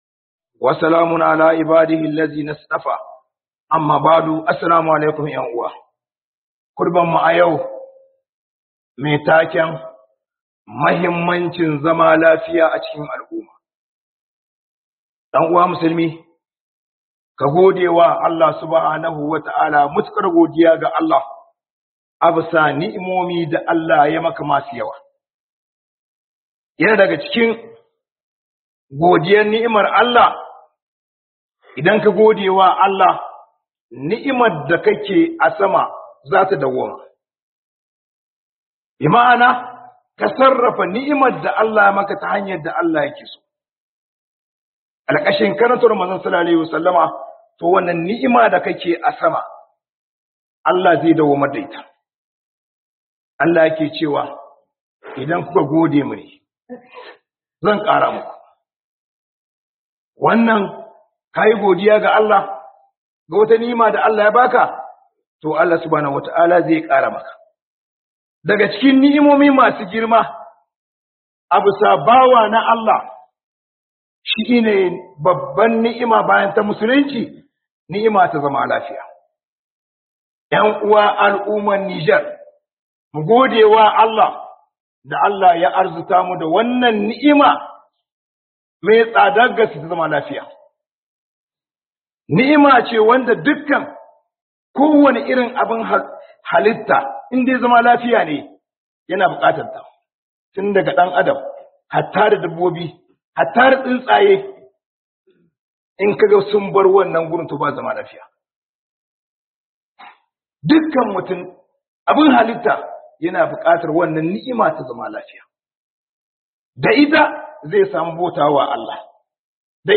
KhuɗubarJuma'a Muhimmancin zama lafiya a cikin gari
HUDUBA